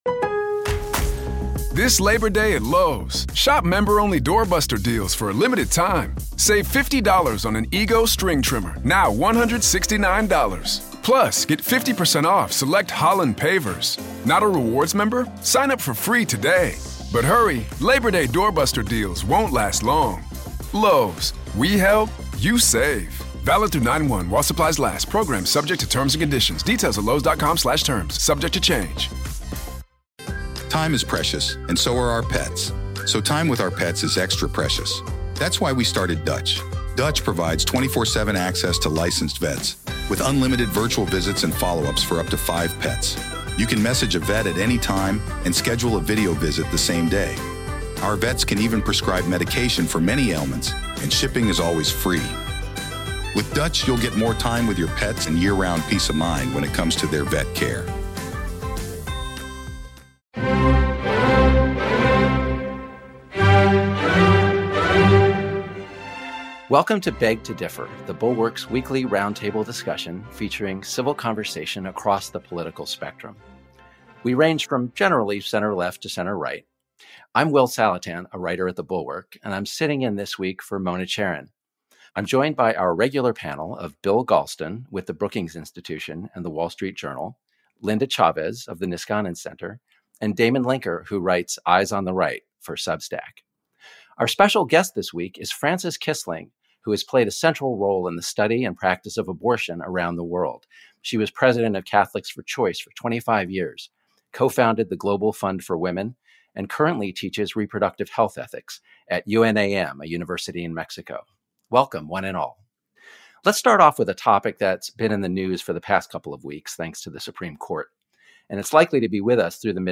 After a multi-decade project to reverse Roe, the two biggest issues right now are interstate travel and pregnancy-terminating pills. Guest host Will Saletan, guest Frances Kissling, and the panel address abortion access and the politics of it all.